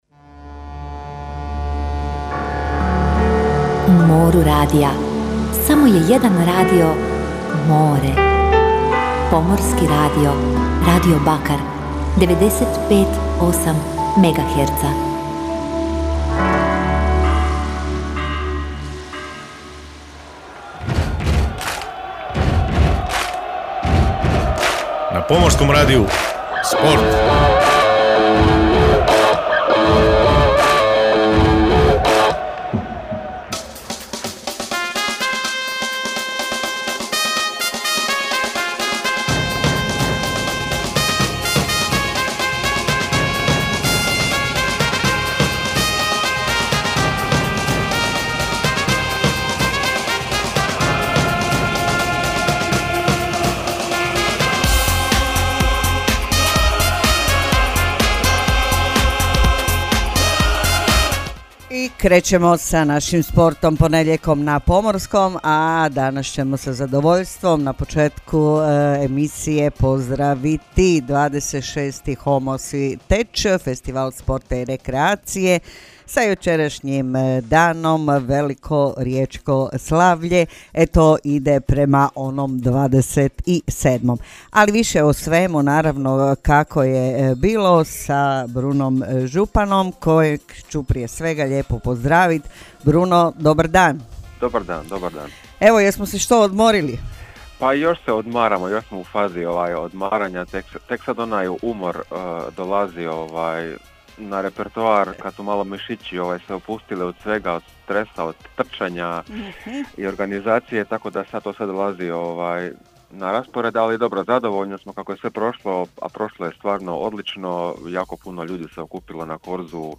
Poslušajte iz prve ruke uživo izjave trenera klubova!